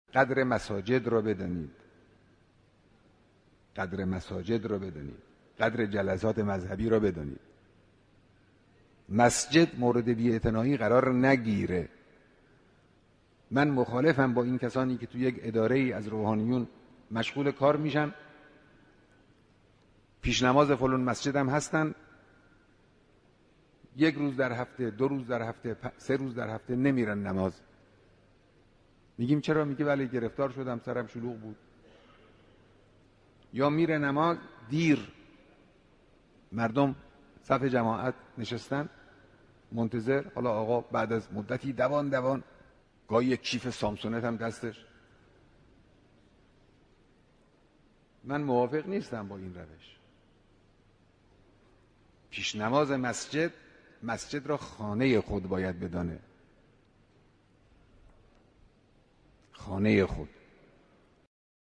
سخنان رهبری